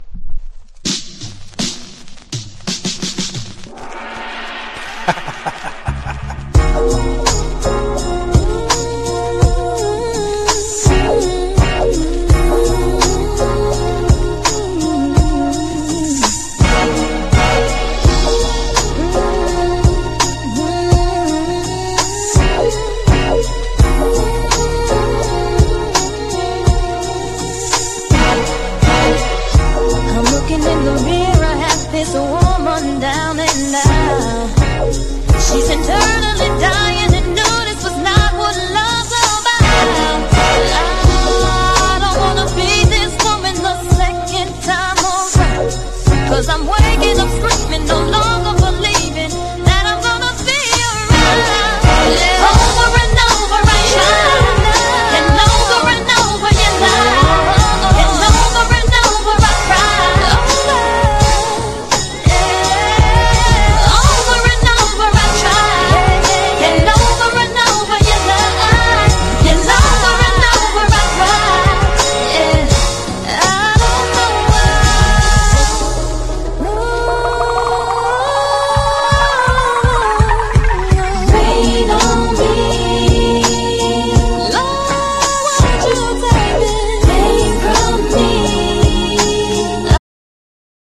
しっとりとしたヴォーカルが切なさを誘うミディアム･メロウ！